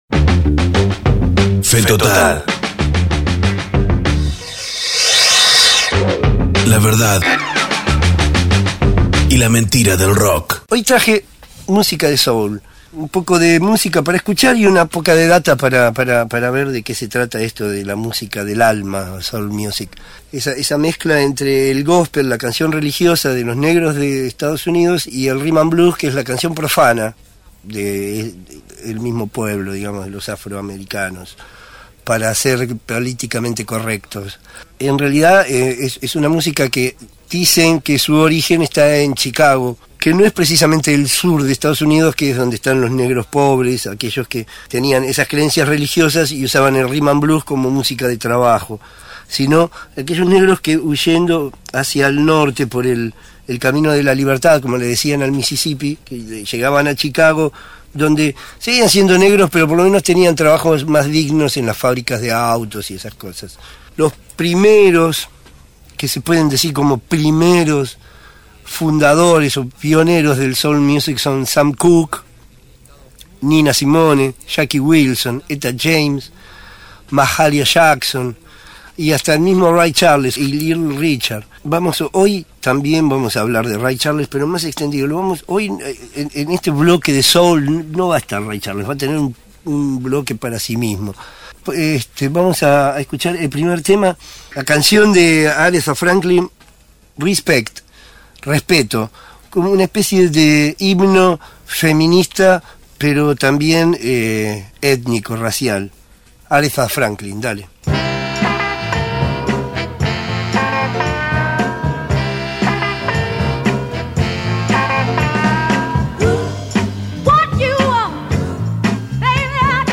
Producción: Radio Universidad Nacional de La Plata